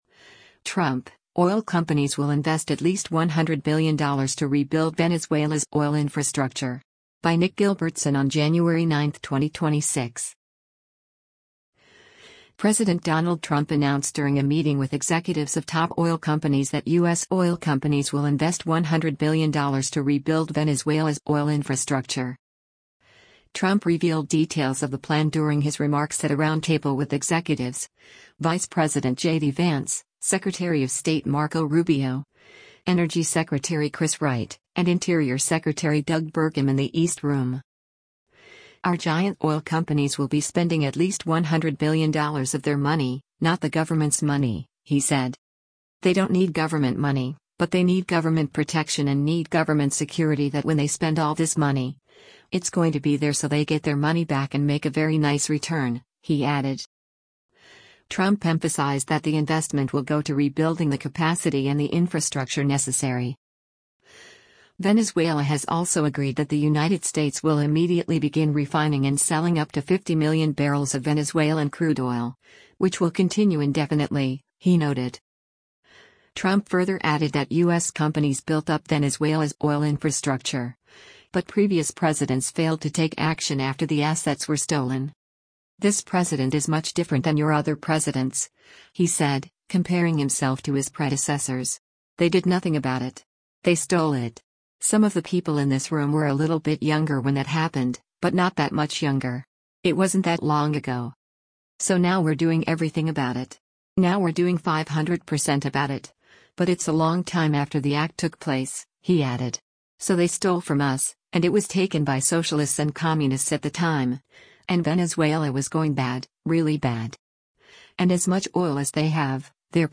Trump revealed details of the plan during his remarks at a roundtable with executives, Vice President JD Vance, Secretary of State Marco Rubio, Energy Secretary Chris Wright, and Interior Secretary Doug Burgum in the East Room.